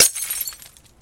bottle break